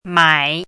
chinese-voice - 汉字语音库
mai3.mp3